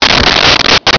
Sfx Poof3
sfx_poof3.wav